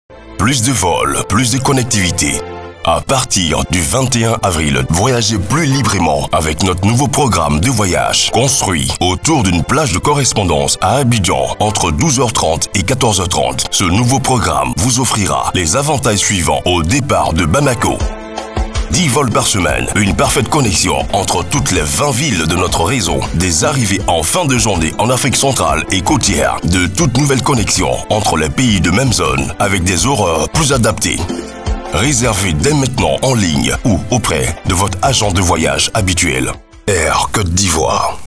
À l’occasion du lancement de son nouveau programme de vols, notre agence a réalisé un spot publicitaire radio diffusé sur les principales stations partenaires, ainsi qu'une vaste opération d’achat d’espace pour maximiser l’impact de la campagne.